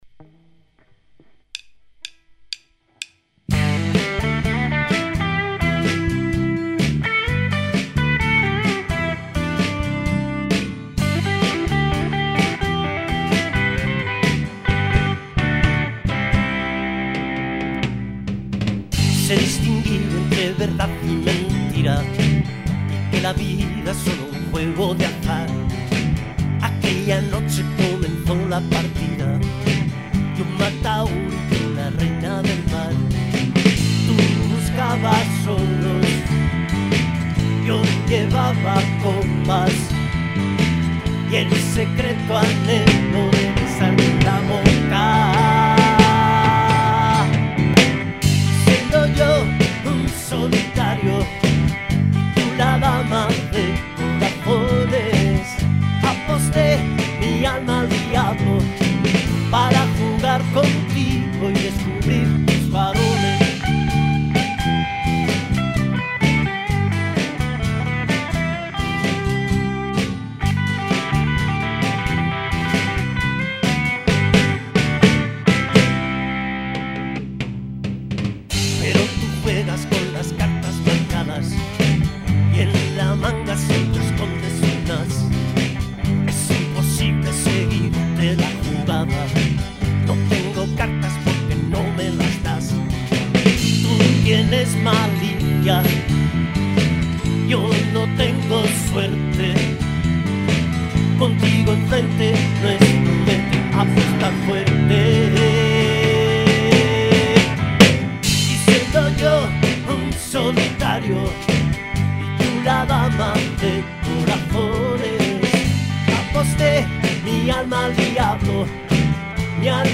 Batería y segundas voces
Guitarra eléctrica Gibson Les Paul
Bajo Yamaha
Guitarra acústica de 12 cuerdas Framus